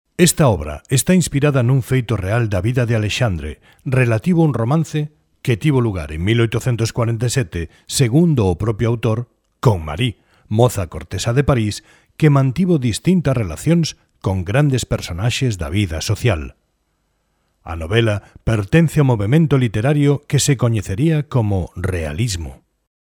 Galician male voice overs